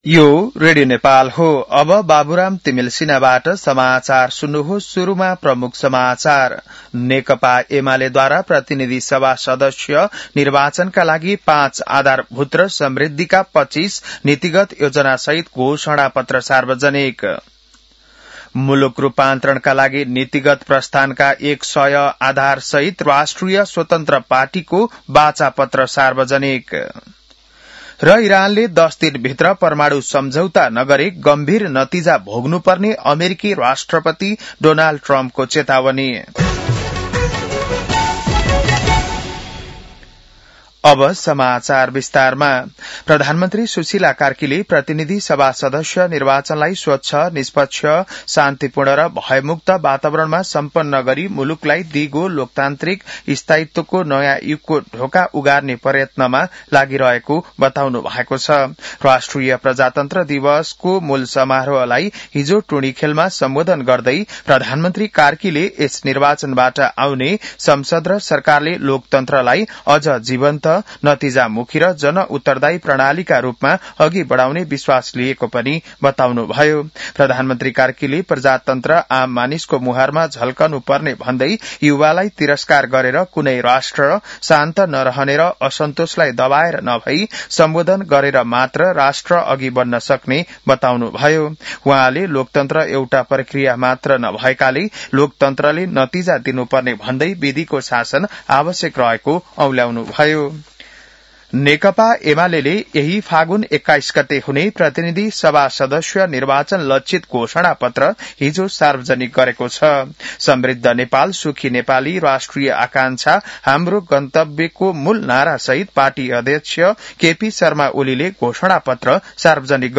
बिहान ९ बजेको नेपाली समाचार : ८ फागुन , २०८२